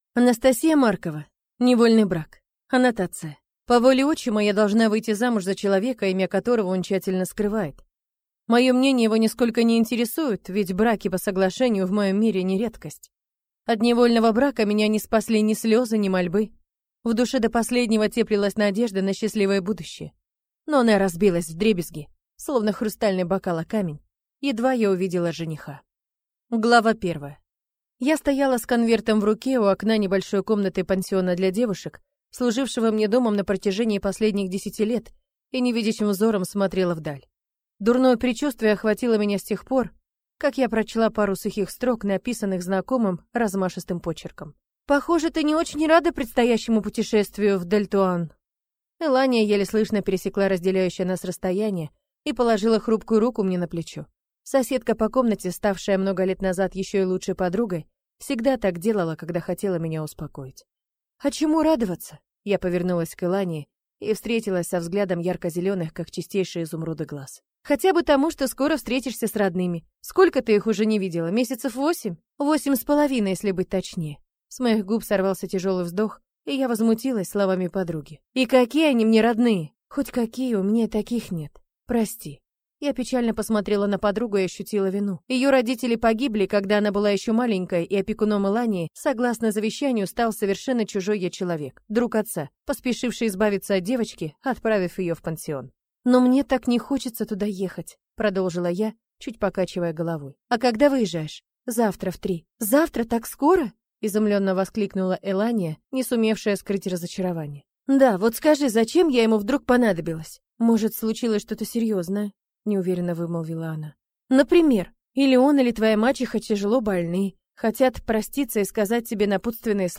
Aудиокнига Невольный брак